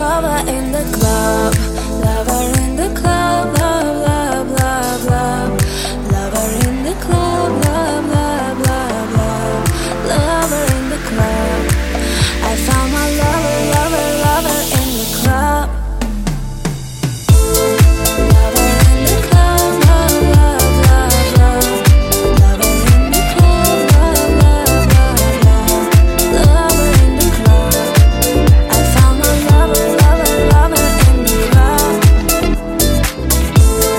Рингтон с приятным женским голосом